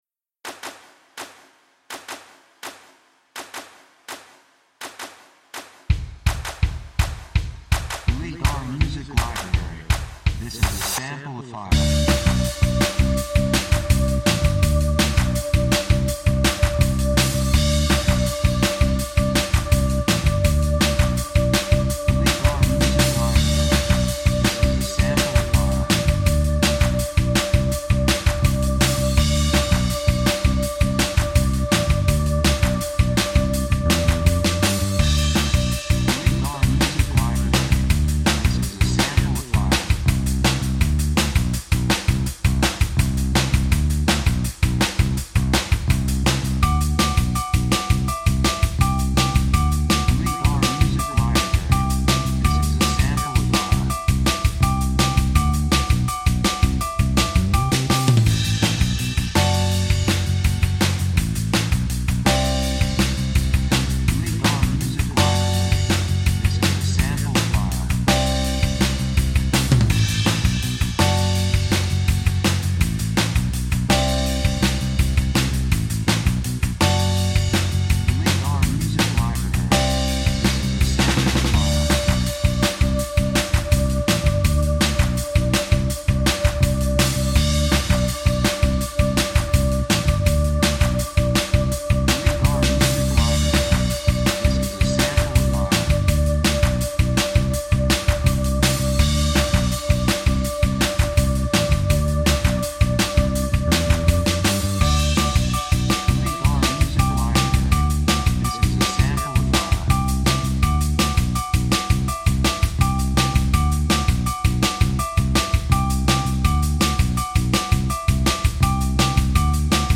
3:25 165 プロモ, ロック